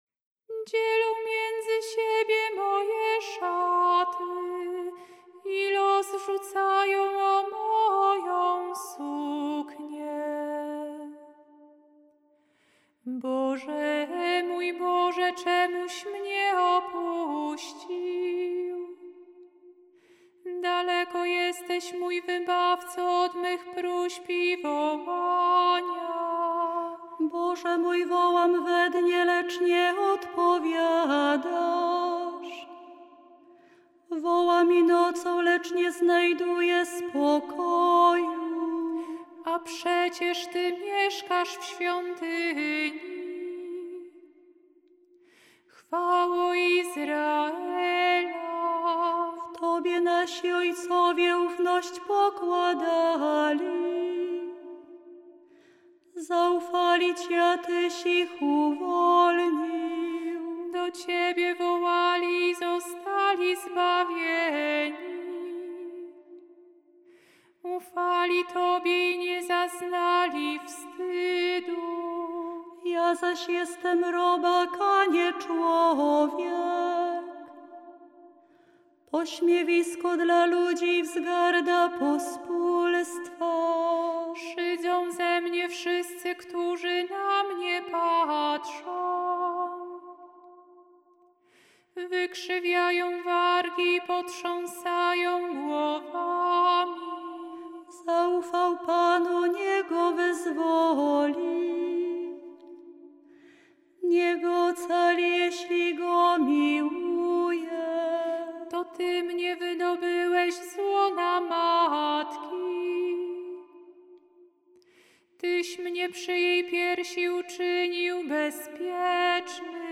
Dlatego do psalmów zastosowano tradycyjne melodie tonów gregoriańskich z ich różnymi, często mniej znanymi formułami kadencyjnymi (tzw. dyferencjami).
Dla pragnących przygotować się do animacji i godnego przeżycia tych wydarzeń liturgicznych przedstawiamy muzyczne opracowanie poszczególnych części wykonane przez nasze siostry